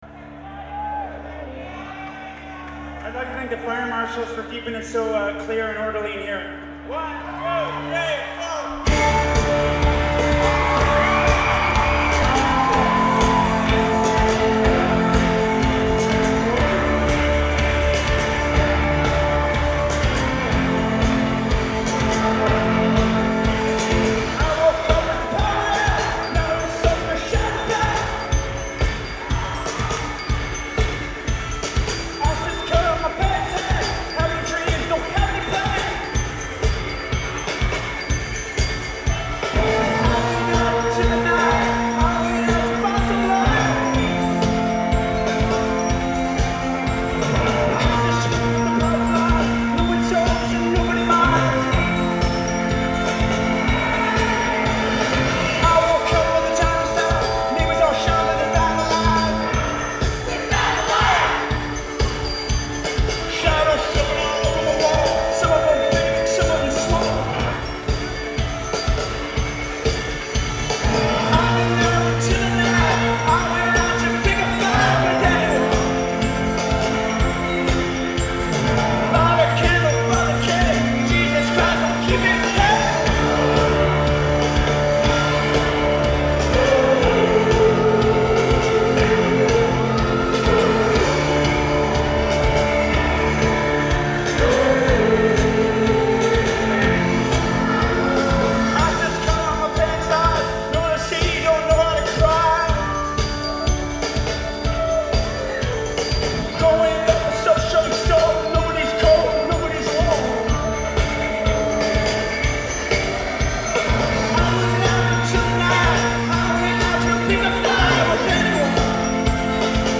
Live at the Orpheum
in Boston, Massachusetts